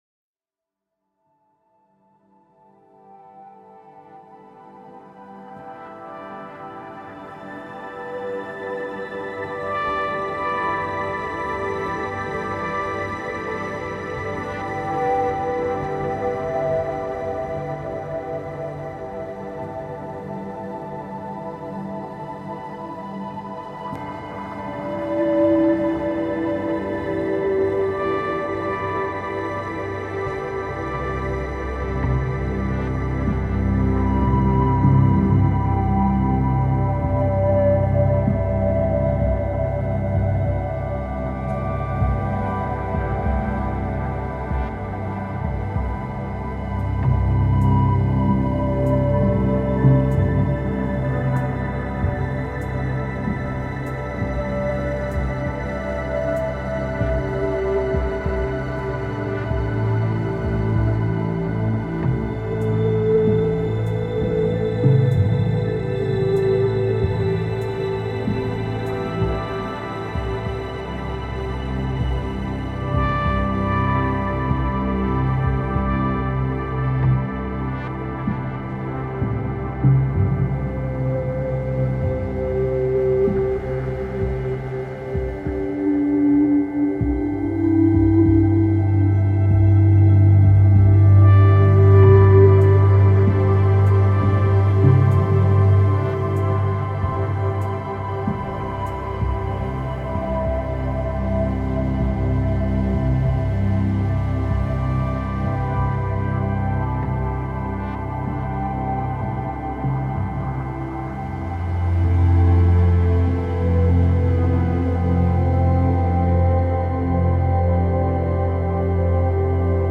Cette fréquence vibratoire élimine le trouble gastro intestinale
FRÉQUENCES VIBRATOIRES